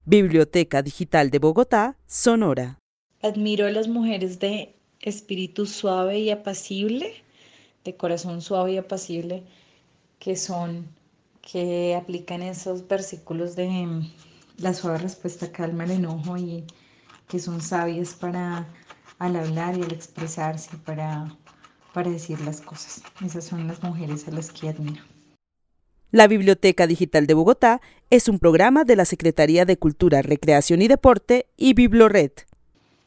Narración oral de una mujer que vive en la ciudad de Bogotá quien admira a las mujeres suaves y apacibles del corazón y que aplican una suave respuesta parar calmar el enojo, y a las que son sabias para decir las cosas. El testimonio fue recolectado en el marco del laboratorio de co-creación "Postales sonoras: mujeres escuchando mujeres" de la línea Cultura Digital e Innovación de la Red Distrital de Bibliotecas Públicas de Bogotá - BibloRed.